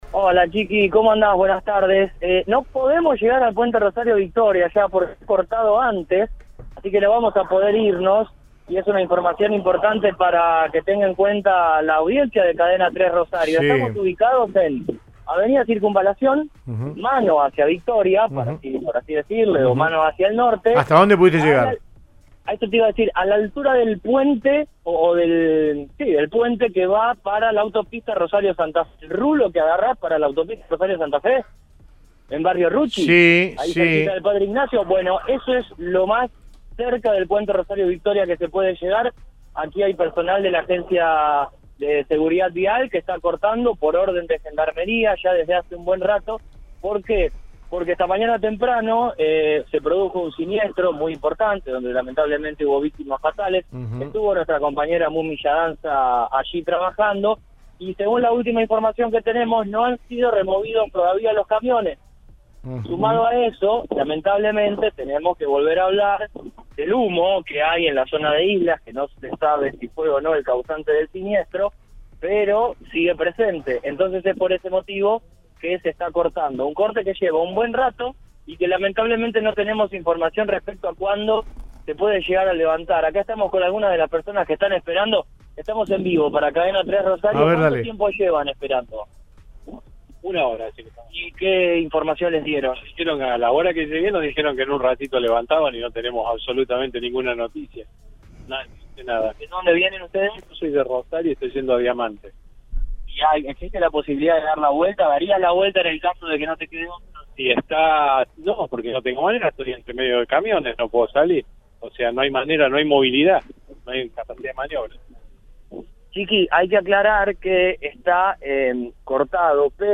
En Avenida de Circunvalación hay cortes y demoras en el tránsito en mano que va hacia la ciudad de Victoria debido a un siniestro vial que se produjo por la mañana cuando chocaron de frente dos camiones y sumado al humo reinante en el lugar por los incendios de campos linderos a la ruta 174 en el kilómetro 14 de la misma según el informe del móvil de Cadena 3 Rosario.